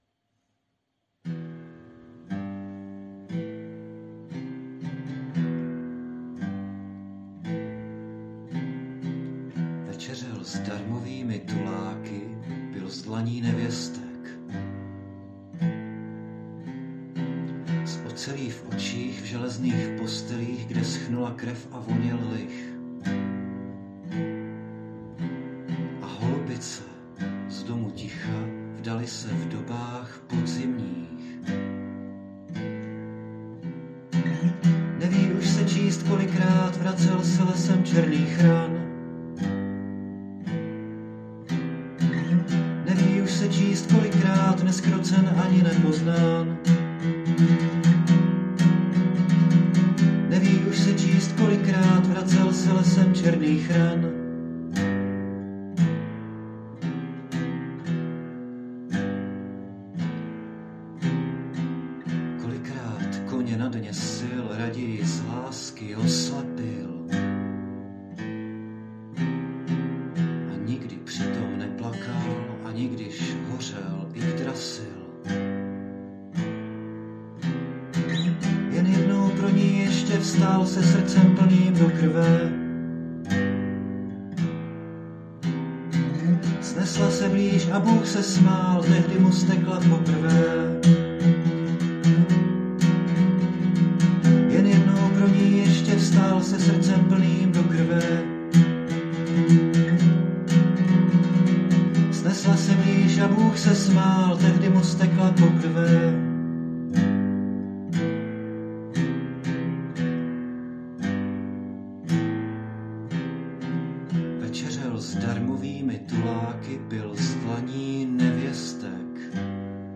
Anotace: Už tady byla, ale teď ji vkládám zhudebněnou..